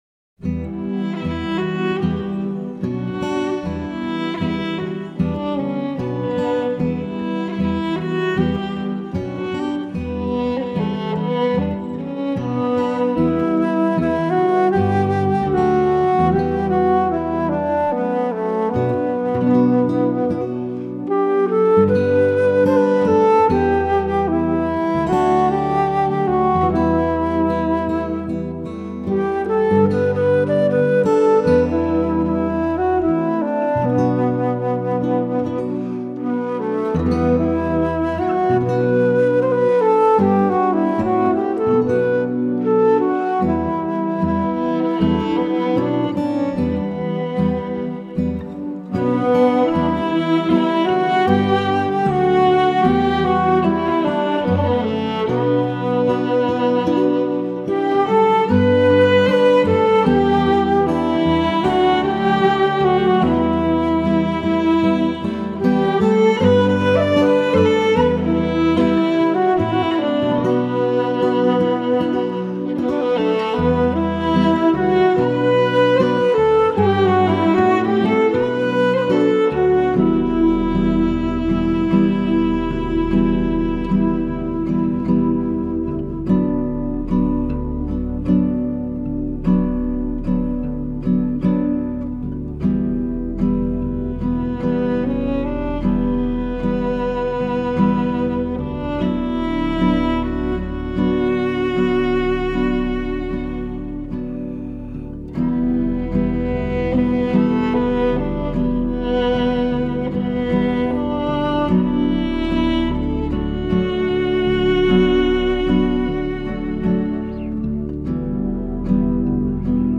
别名：凯尔特音乐
清秀的乐调百转回肠，哀怨的诉说着大地的情思，山的朗润。丽练的女声，柔情缠绵
祈福宁详的音乐，淡雅别致的吉他声诉，回想在脑海。